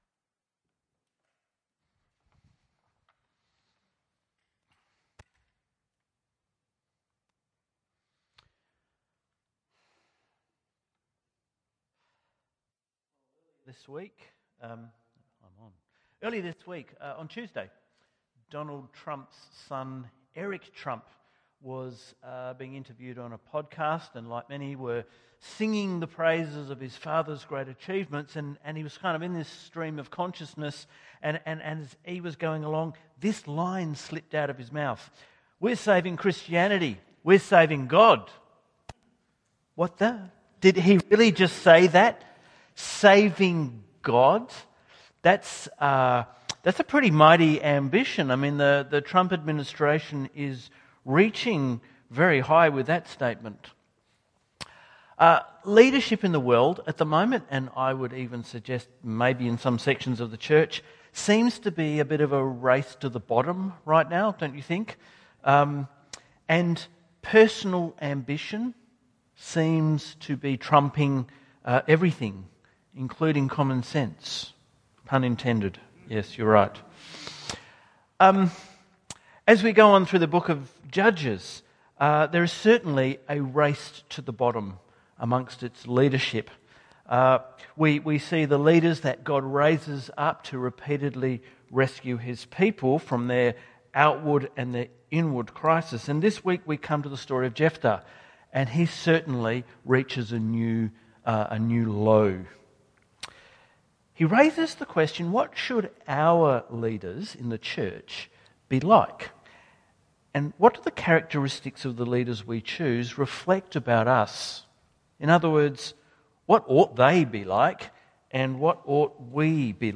St Barnabas Sermons Podcast - Jephthah: Faith and Folly | Free Listening on Podbean App